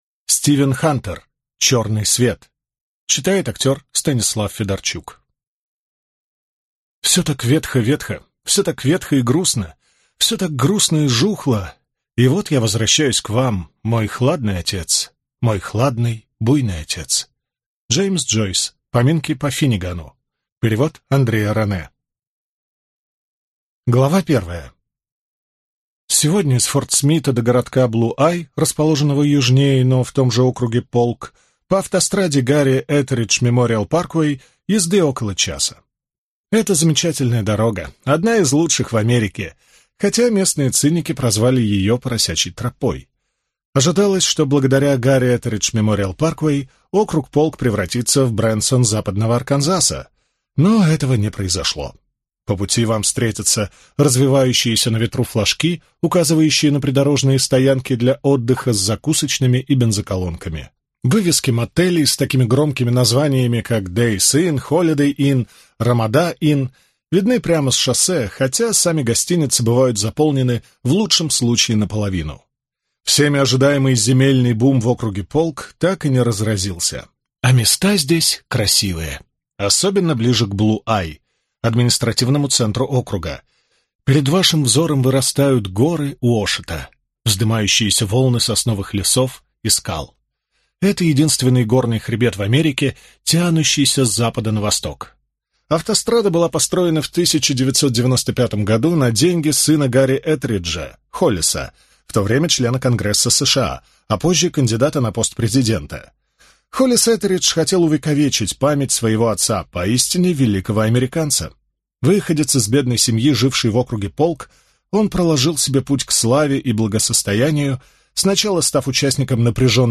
Аудиокнига Черный свет | Библиотека аудиокниг